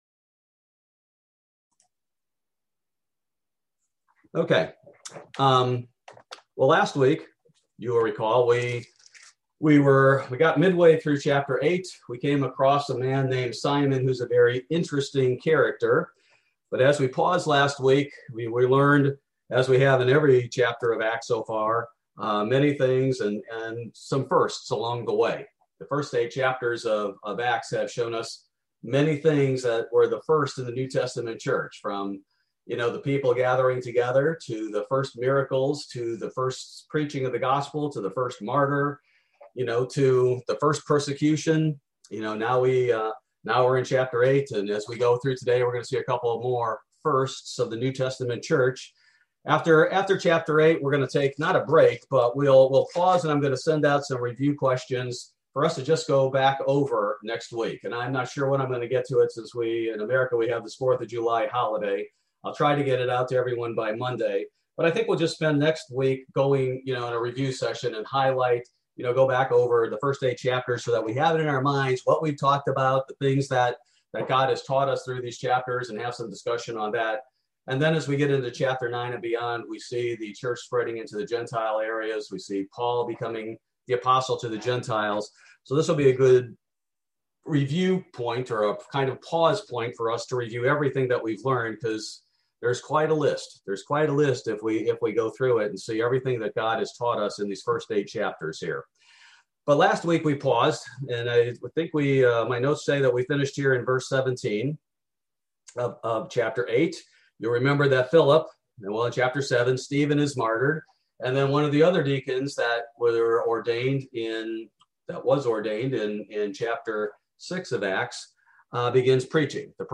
Bible Study: June 30, 2021